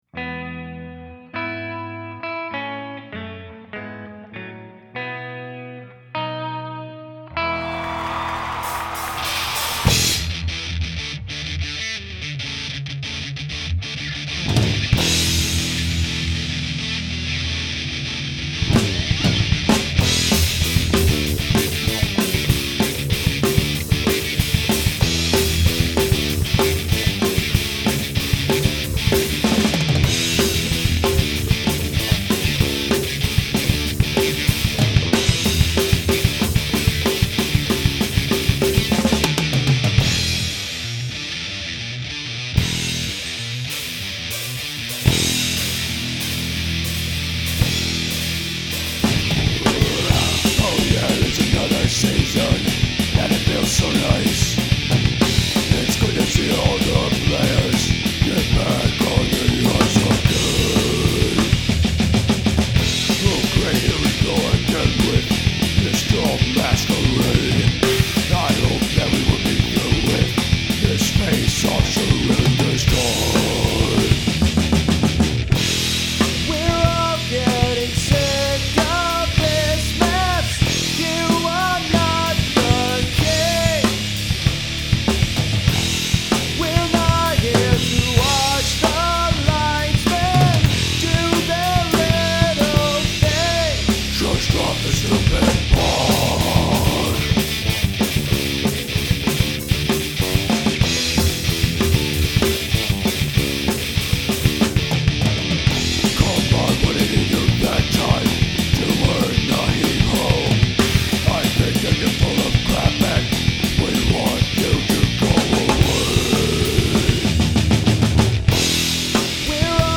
A little bit of the Canadian national anthem, a little bit of speed metal, and a long intro are in the mix for this song. Lyric-wise, I decided to write about the drastic spike in “guys getting kicked out of face-offs” that started last season.